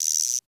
Index of /90_sSampleCDs/Roland L-CDX-01/DRM_Drum Machine/KIT_CR-78 Kit